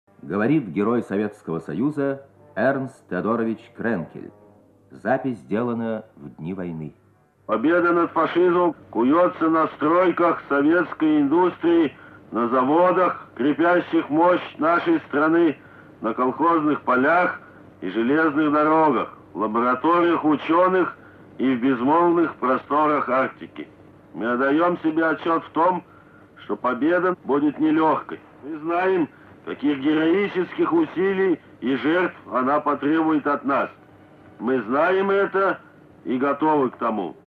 Знаменитый советский полярник, герой Советского Союза Эрнст Кренкель рассказывает об усилиях советского народа для достижения победы в Великой Отечественной Войне.
Архивная запись, сделанная в дни войны.